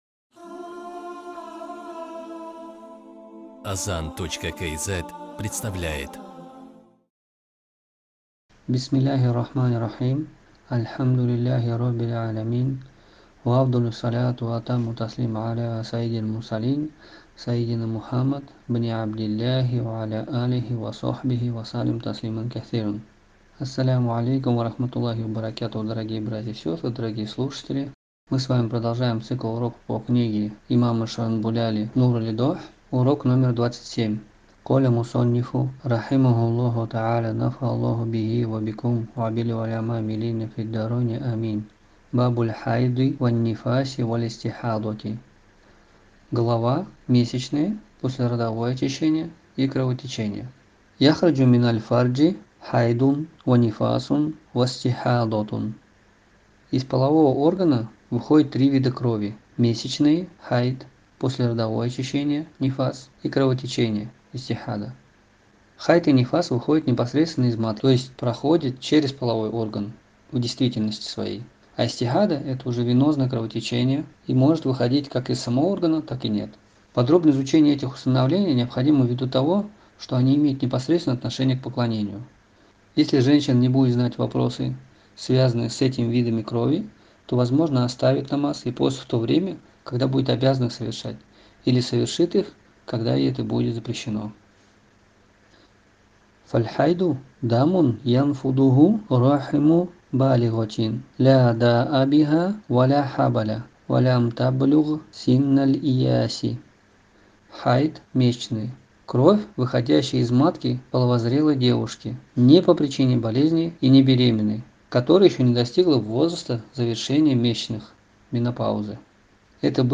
Дәрістер топтамасы